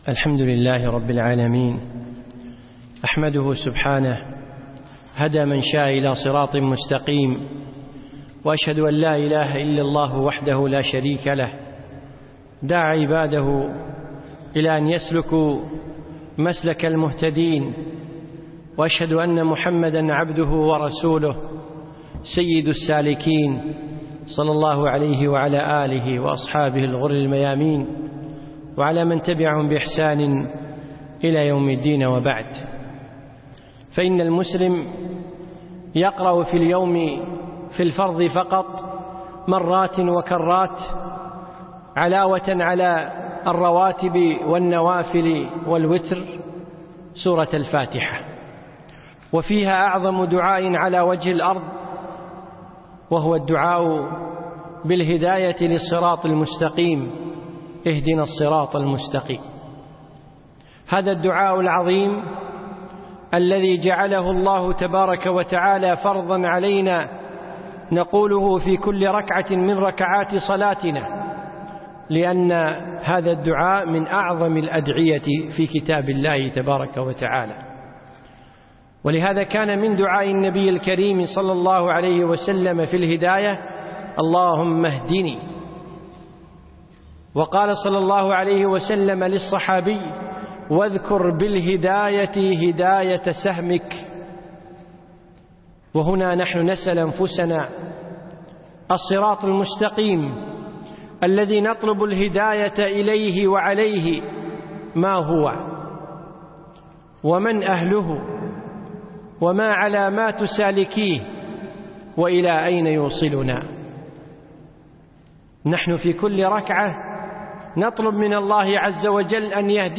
من دروس الشيخ في دولة الإمارات في رمضان 1436